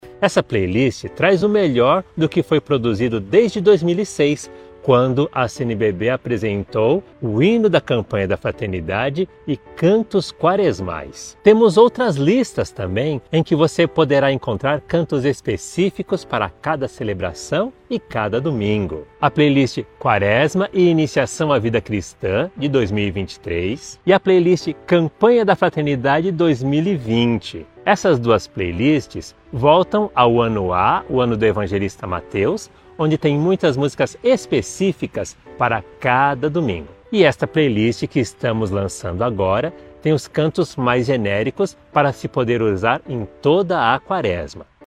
SONORA-PADRE-.mp3